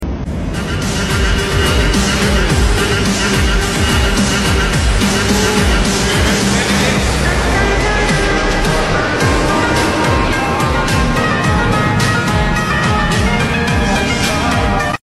Segmen Persembahan Udara Flypast Merdeka 2025 Ini Membawa Kareografi Cantik Dimana Pesawat Su 30 MKM Terbang Lebih Rendah Dari Kebiasaan